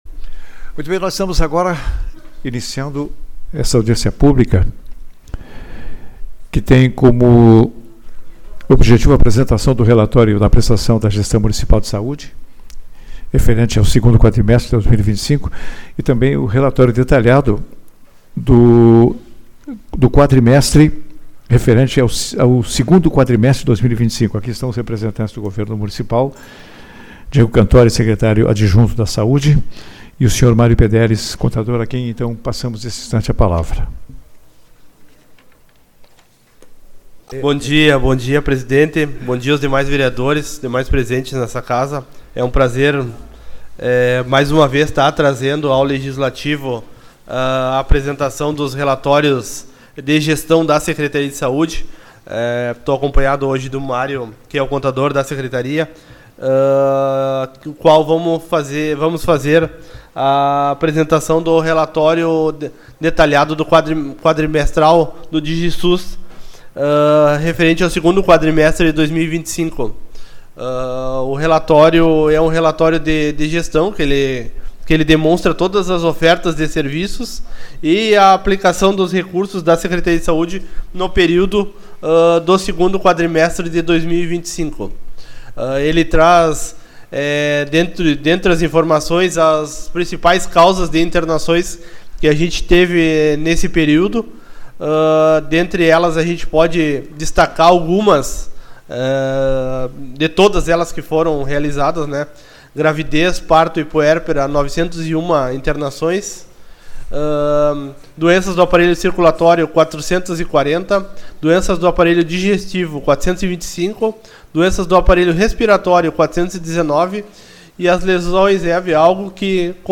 Tipo de Sessão: Audiência Pública